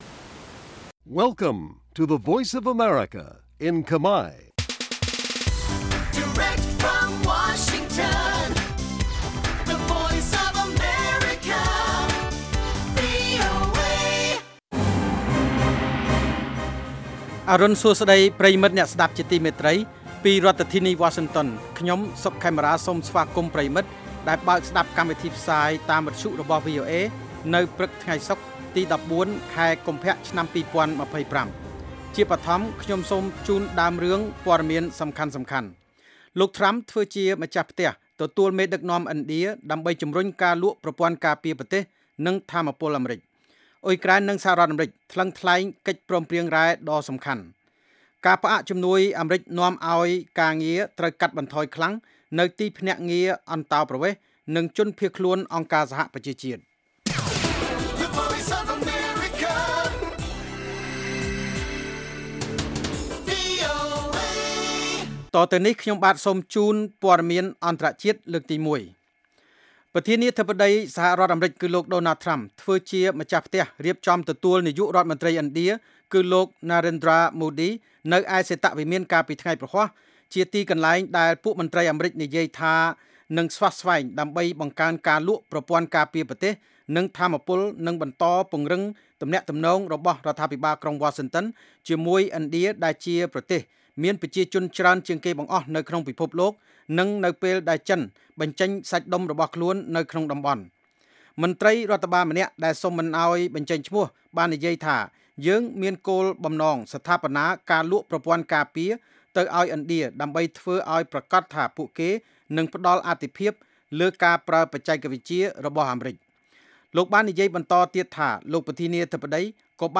ព័ត៌មានពេលព្រឹក១៤ កុម្ភៈ៖ អ្នករិះគន់ទីភ្នាក់ងារ USAID អំពាវនាវឲ្យវិនិយោគដោយមានគោលដៅជាក់លាក់នៅបរទេស